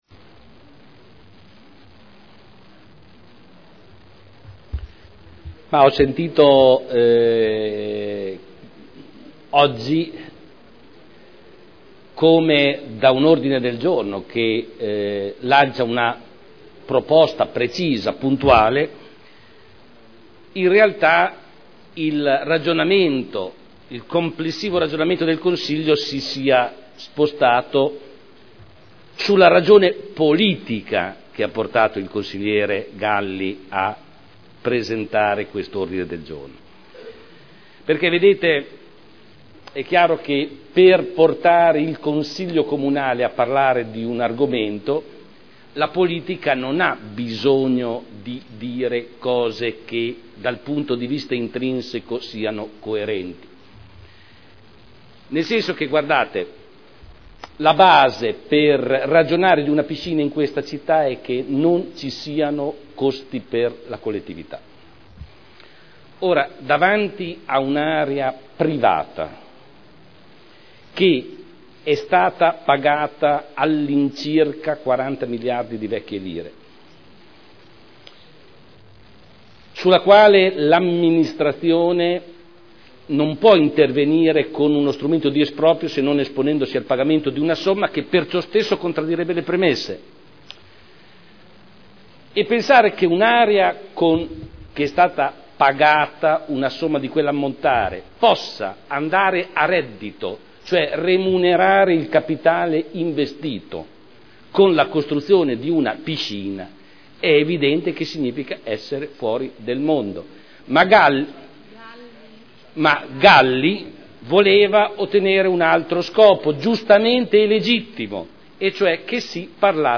Seduta del 10/01/2011.
Audio Consiglio Comunale